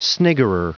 Prononciation du mot sniggerer en anglais (fichier audio)
Prononciation du mot : sniggerer